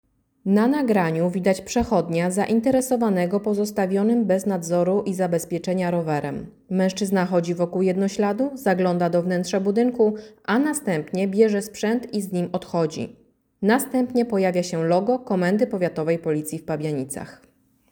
Nagranie audio Audiodeskrypcja nagrania